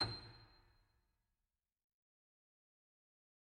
SoftPiano
b6.mp3